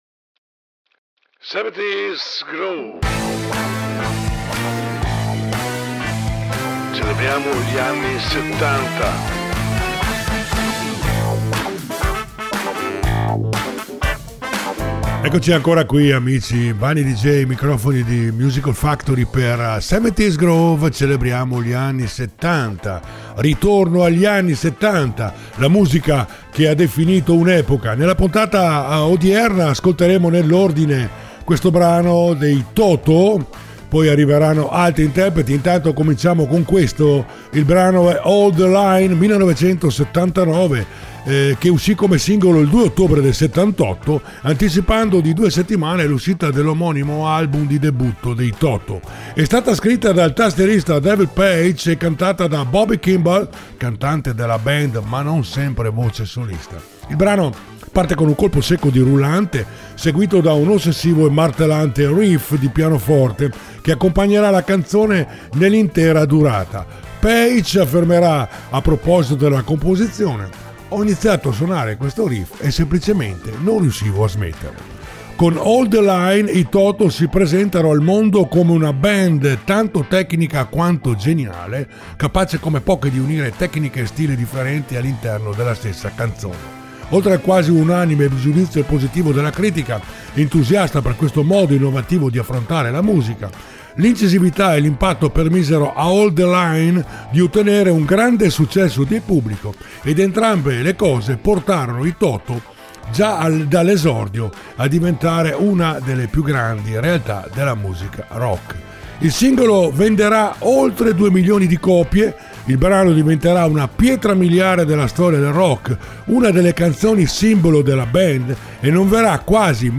70's Groove